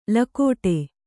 ♪ lakōṭe